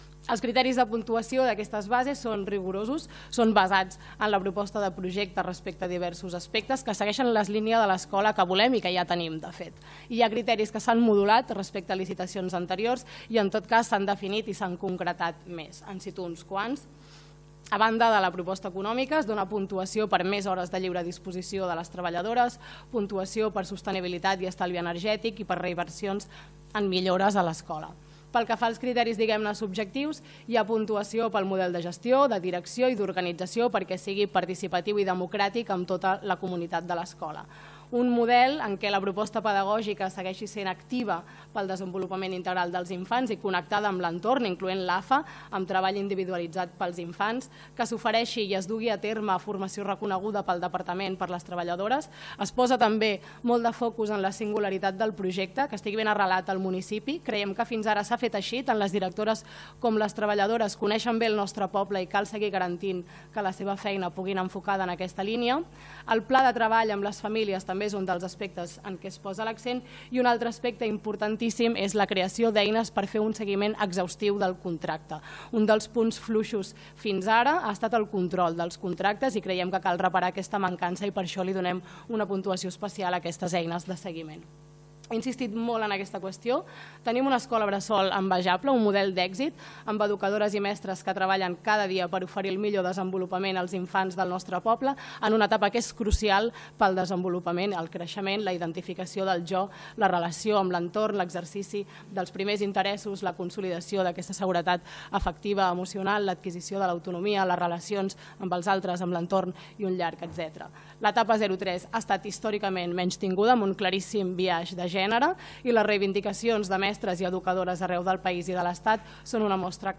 Serrat va detallar que les noves bases modulen diversos criteris de puntuació, com el de comptar amb més hores de lliure disposició de les treballadores, i posen el focus en la singularitat del projecte, és a dir, “que estigui ben arrelat al municipi”; i en la creació d’eines “per fer un seguiment exhaustiu del contracte”: